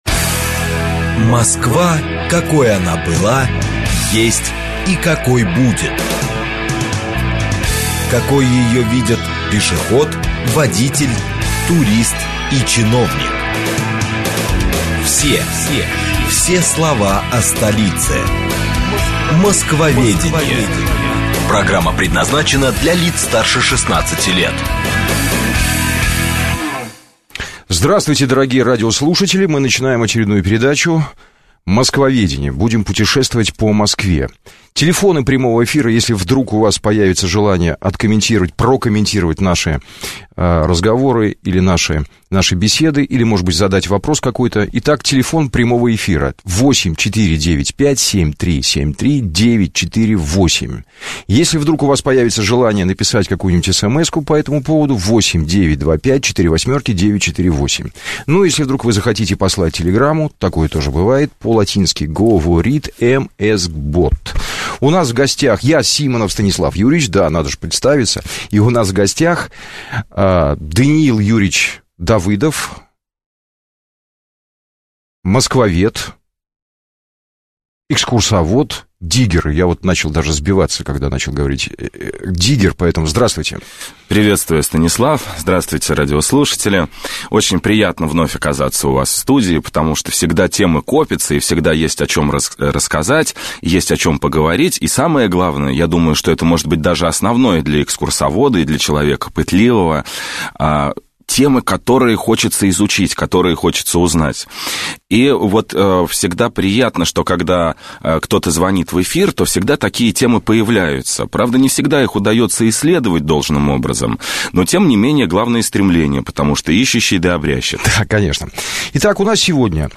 Аудиокнига Ваганьковский холм | Библиотека аудиокниг